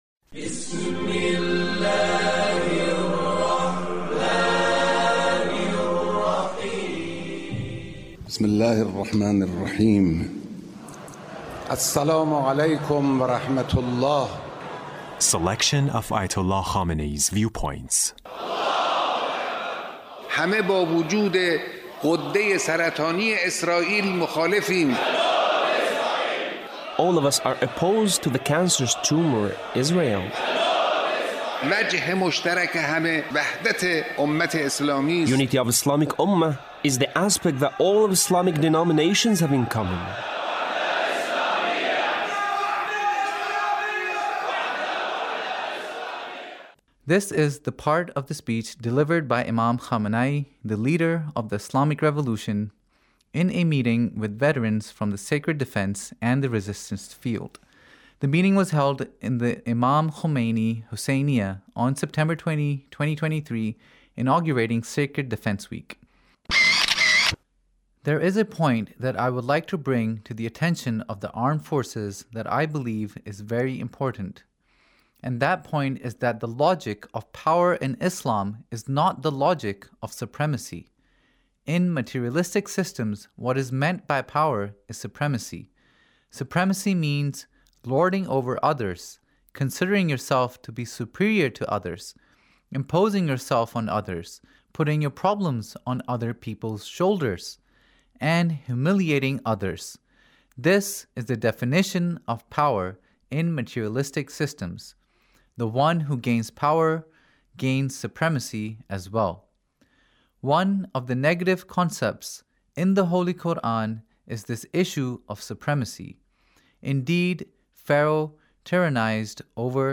Leader's Speech about Sacred defense